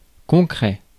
Ääntäminen
Synonyymit existent Ääntäminen US Haettu sana löytyi näillä lähdekielillä: englanti Käännös Ääninäyte Adjektiivit 1. existant {m} 2. concret {m} France Existing on sanan exist partisiipin preesens.